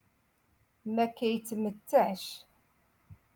Moroccan Dialect-Rotation Six-Lesson Twenty Two